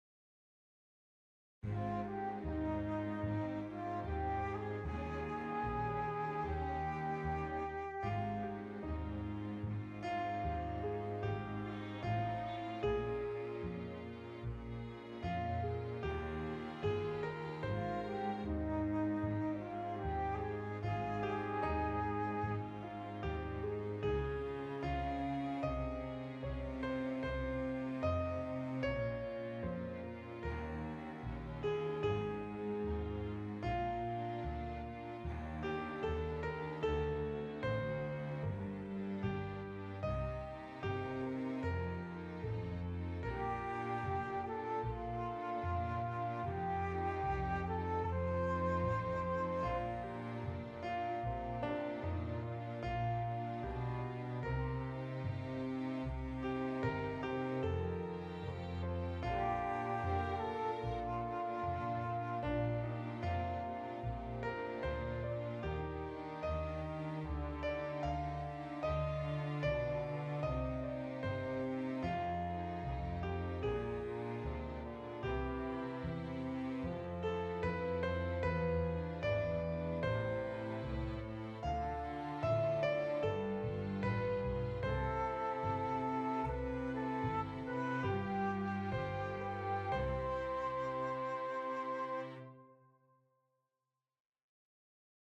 Underscore
Reduced arrangement